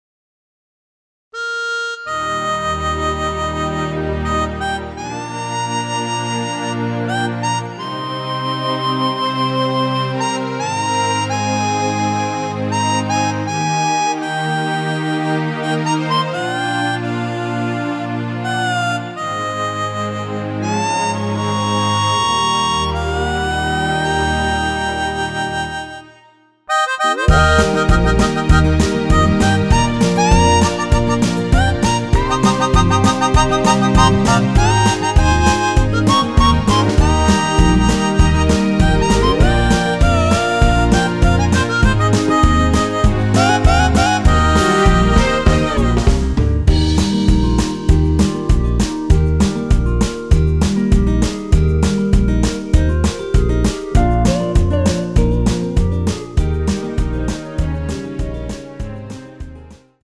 원키에서(+3)올린 멜로디 포함된 MR입니다.(미리듣기 확인)
Eb
멜로디 MR이라고 합니다.
앞부분30초, 뒷부분30초씩 편집해서 올려 드리고 있습니다.
중간에 음이 끈어지고 다시 나오는 이유는